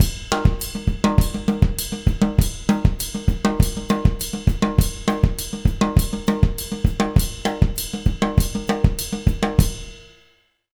100SONGO01-R.wav